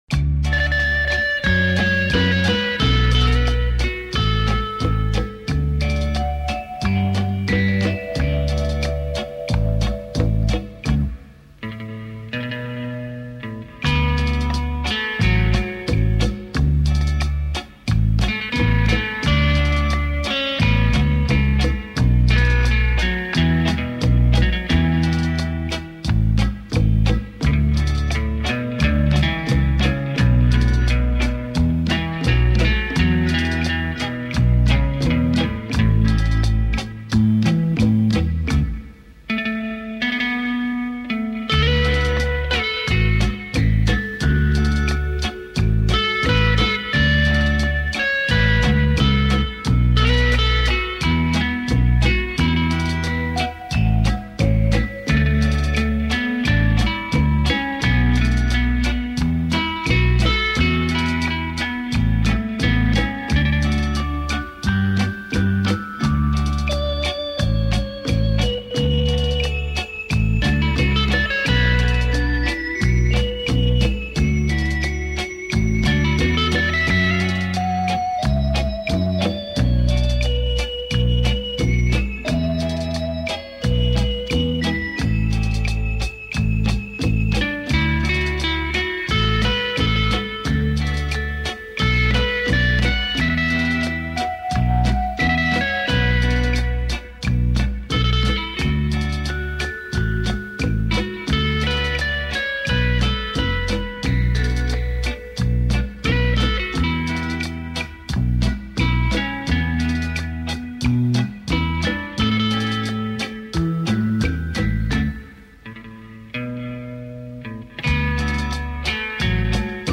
60年代最流行吉他音乐